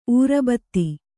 ♪ ūrabatti